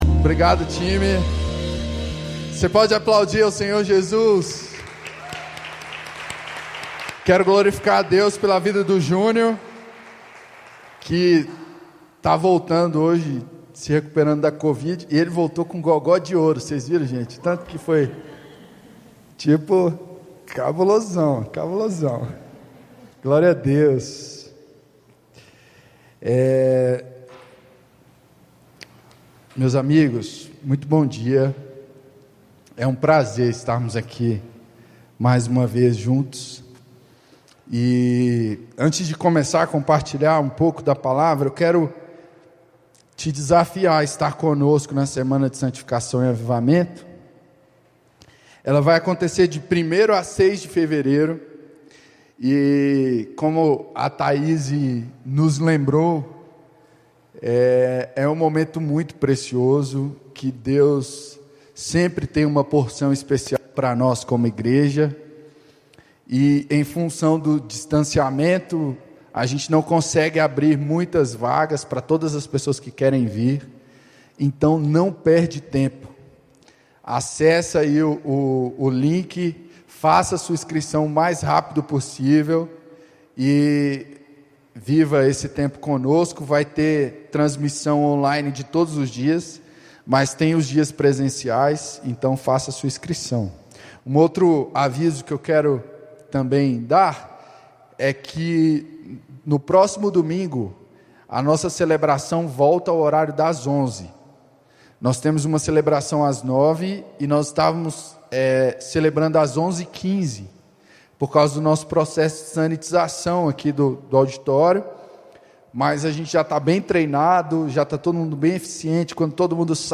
Mensagem apresentada por Igreja Batista Capital como parte da série Recomece na Igreja Batista Capital.